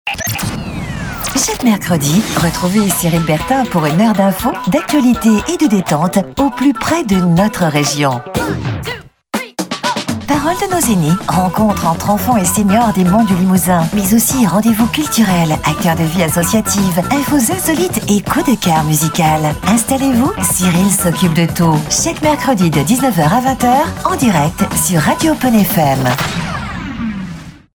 Billboard TV et radio
Billboard-radio.mp3